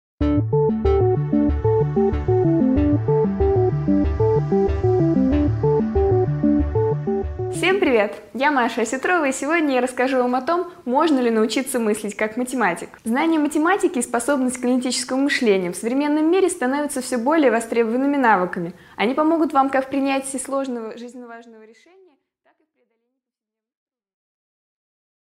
Аудиокнига 5 минут О мышлении | Библиотека аудиокниг